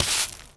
Broom Sweeping
sweeping_broom_leaves_04.wav